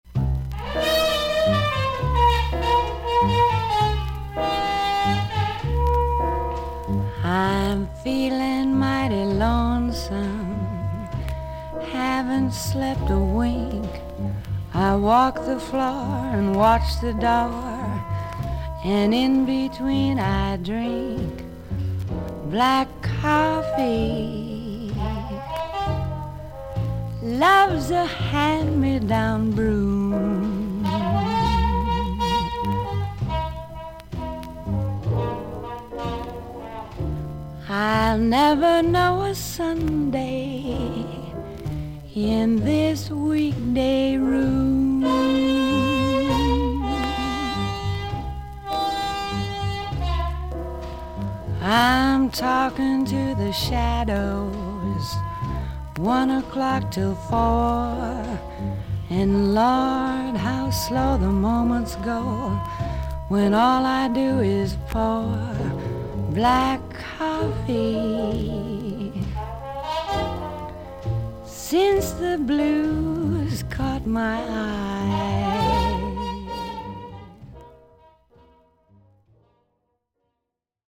少々軽いパチノイズの箇所あり。少々サーフィス・ノイズあり。クリアな音です。
吐息のような歌声の女性ジャズ・シンガー。